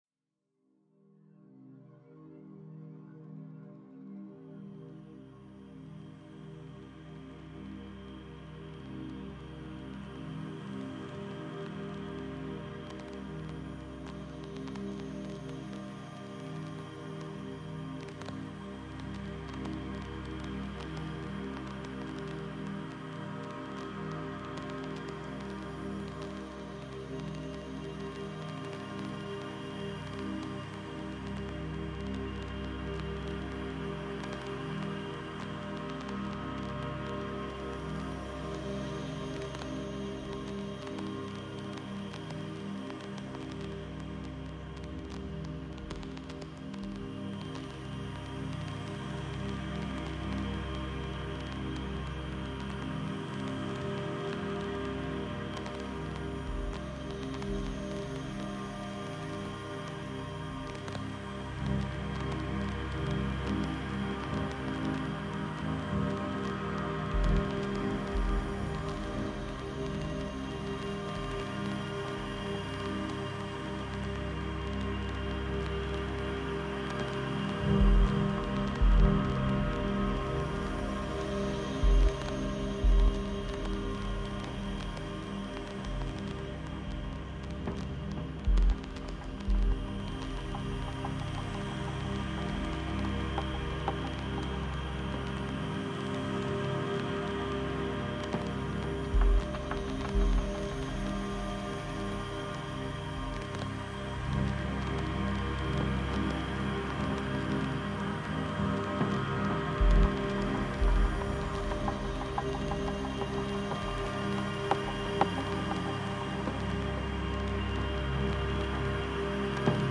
Genre: Dub Techno/Ambient/Drone/Techno.